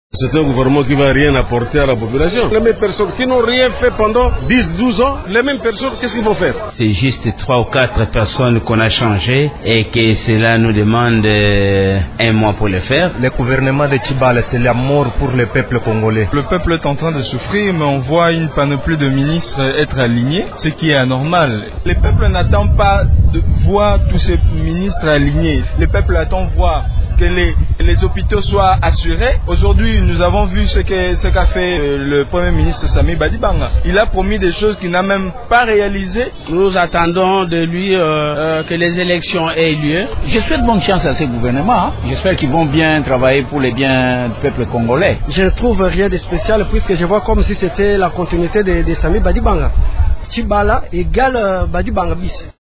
Suivez les réactions des Kinois à la suite de la publication du gouvernement Tshibala.